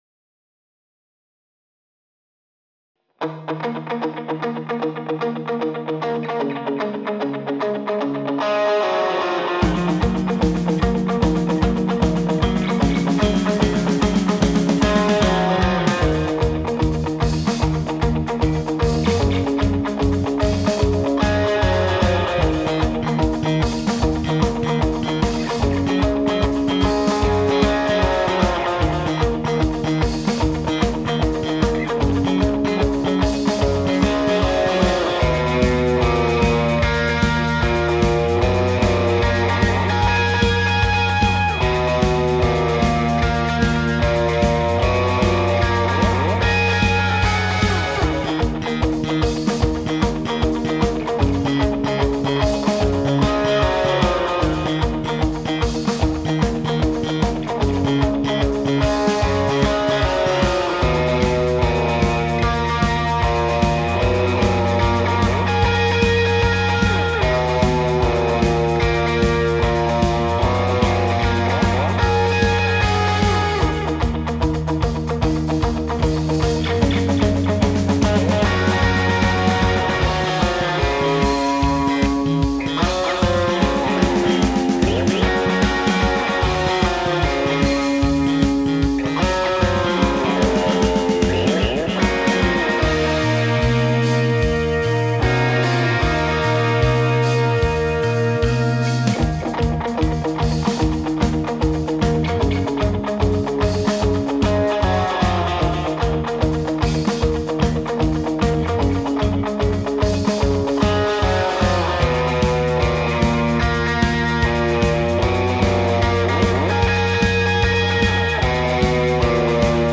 Without vocals
Based on the album and rare live version